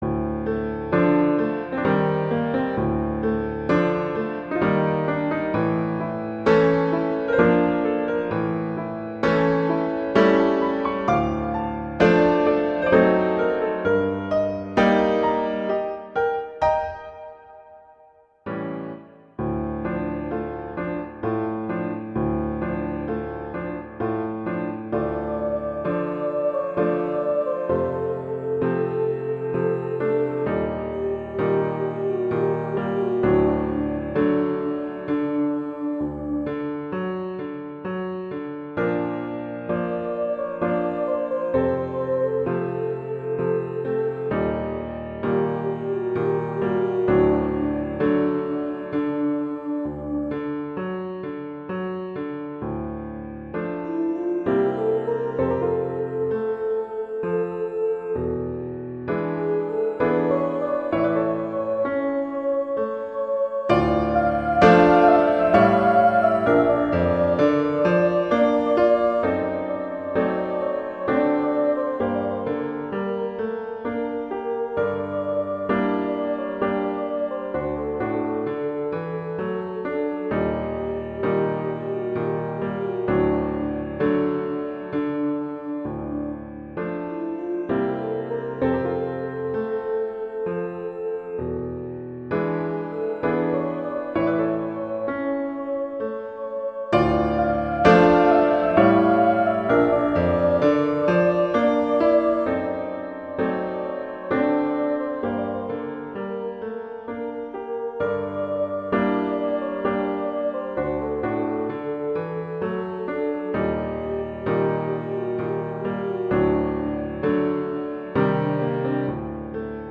classical, world, children